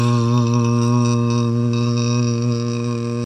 Now try listening to one sound without the beginning and the end.
long-l-clipped.mp3